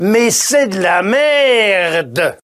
PLAY Money SoundFX